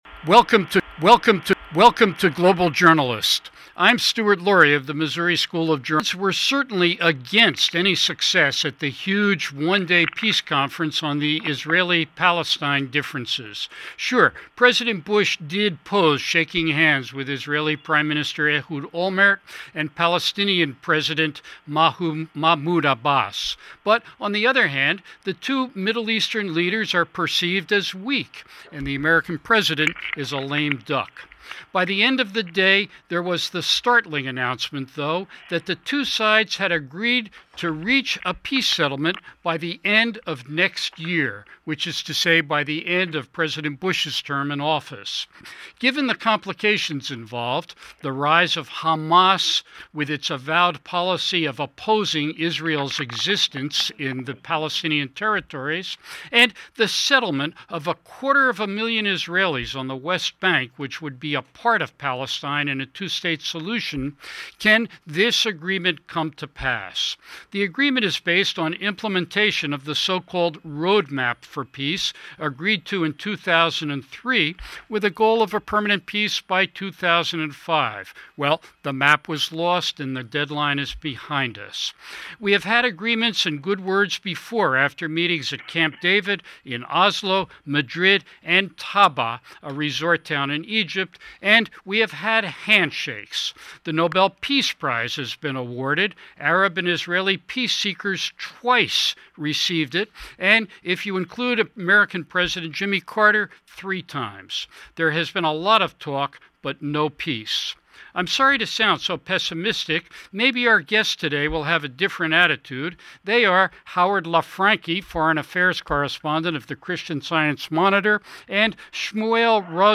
He and his international guests ponder. The American journalist on the panel says the U.S. could have a more active role this time around in helping put an end to this long conflict in the West Bank and Gaza Strip.